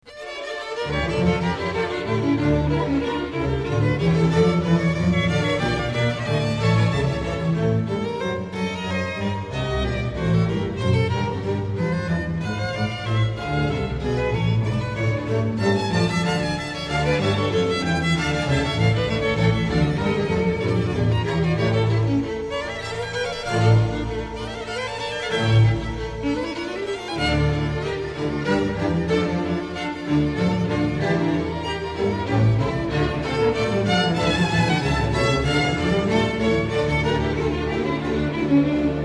a-moll Hegedűverseny